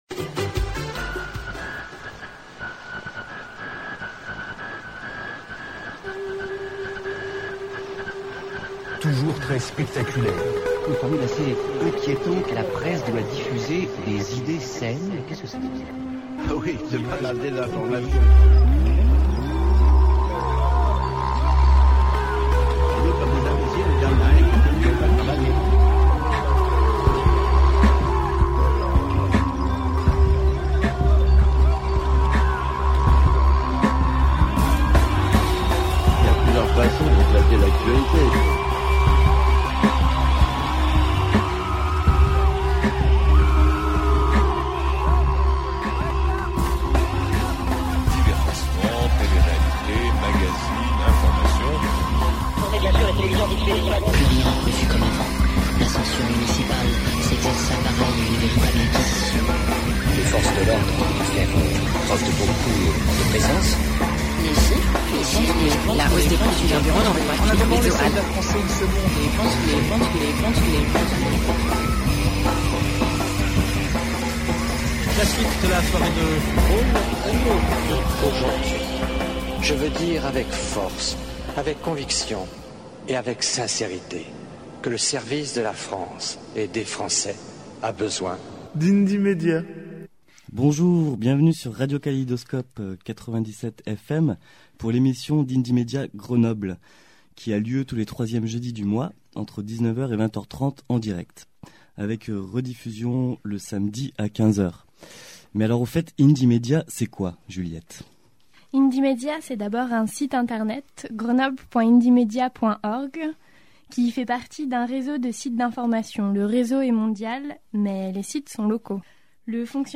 En mai 2006, un mois avant l'inauguration du centre de recherches Minatec, une émission radio d'Indymedia Grenoble interroge la société industrielle grenobloise, les dangers des nanotechnologies, le traitement médiatique autour de Minatec, les raisons de manifester contre l'inauguration de ce pôle européen en nanotechnologies.